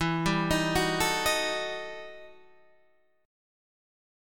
EmM11 chord